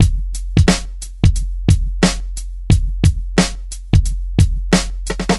• 89 Bpm Breakbeat E Key.wav
Free drum groove - kick tuned to the E note. Loudest frequency: 950Hz
89-bpm-breakbeat-e-key-NIS.wav